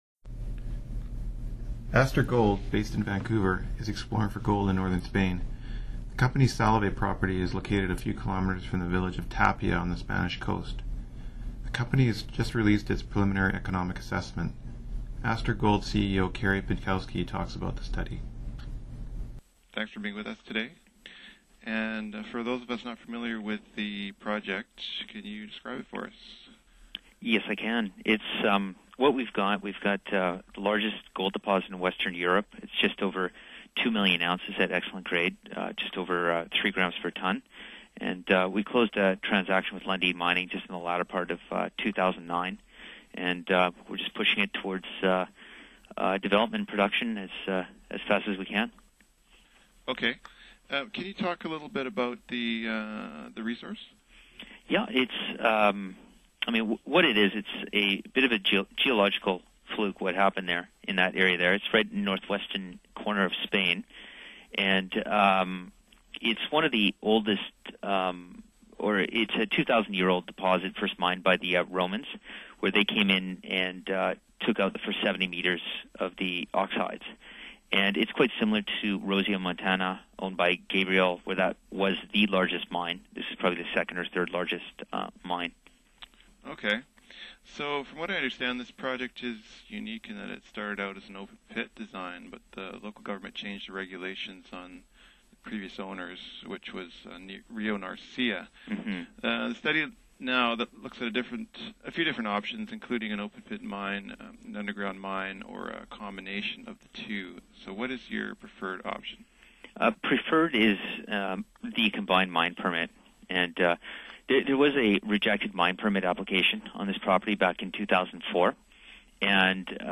Astur Gold Inteview Feb 2011